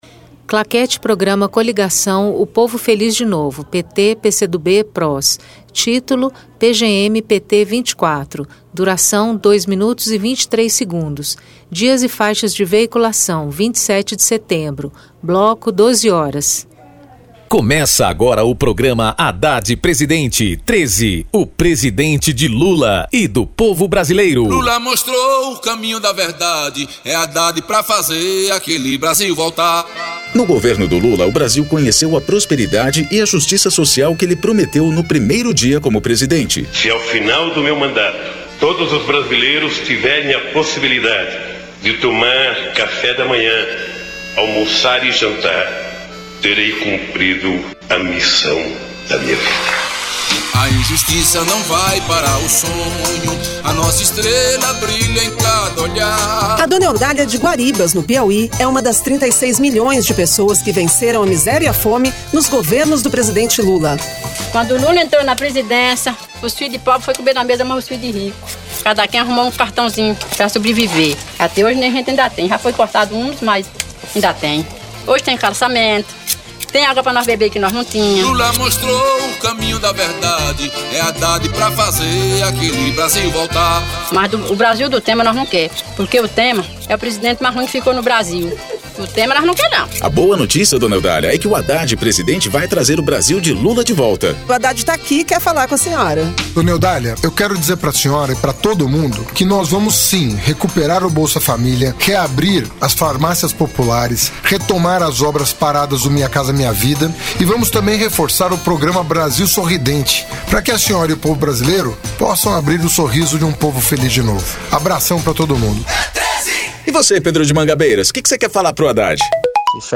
TítuloPrograma de rádio da campanha de 2018 (edição 24)
Descrição Programa de rádio da campanha de 2018 (edição 24) - 1° turno